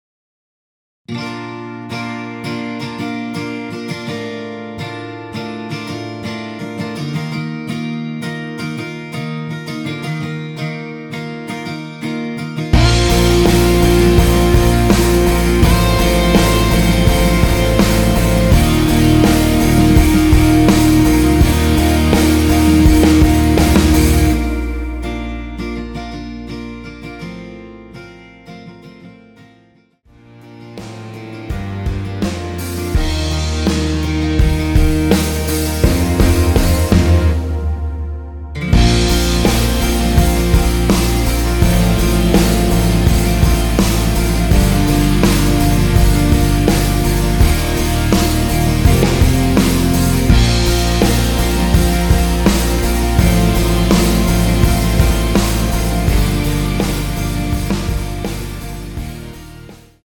원키에서(-2)내린 (1절앞+후렴)으로 진행되게 편곡된 MR입니다.
Bb
◈ 곡명 옆 (-1)은 반음 내림, (+1)은 반음 올림 입니다.
앞부분30초, 뒷부분30초씩 편집해서 올려 드리고 있습니다.